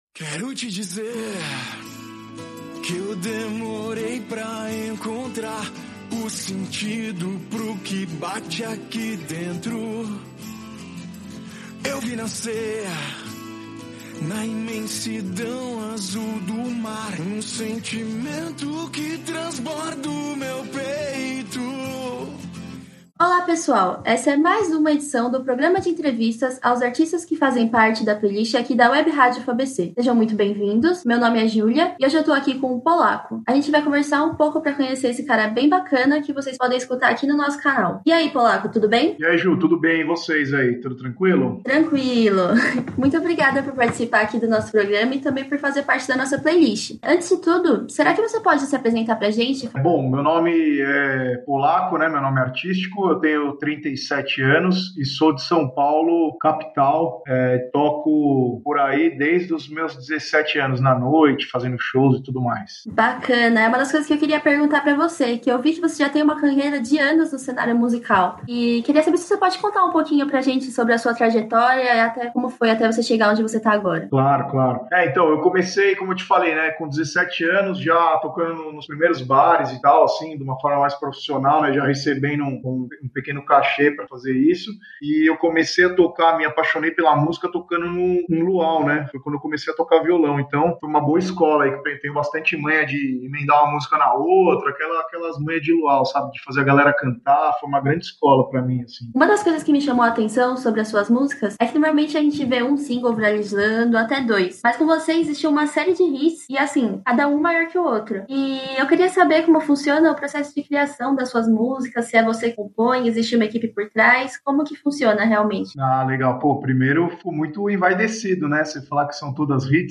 » ENTREVISTAS WEBRÁDIO UFABC